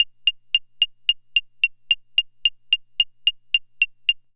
70s Random 110-F.wav